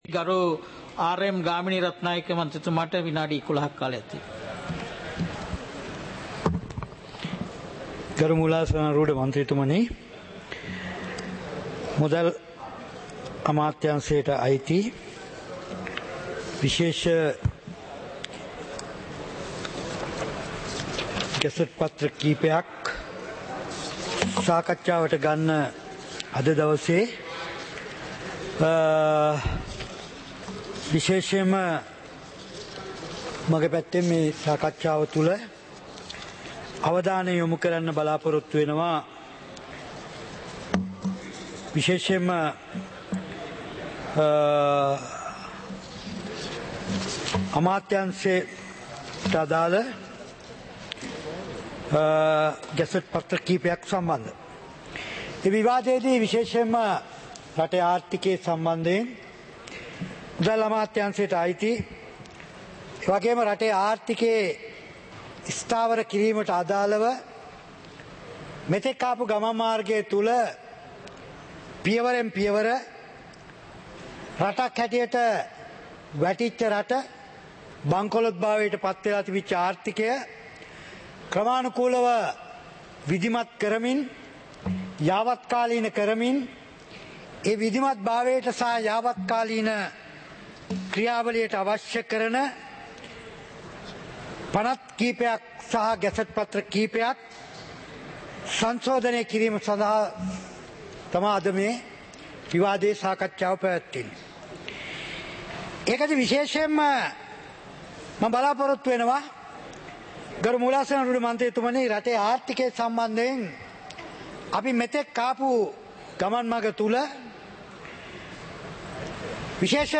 இலங்கை பாராளுமன்றம் - சபை நடவடிக்கைமுறை (2026-02-18)
நேரலை - பதிவுருத்தப்பட்ட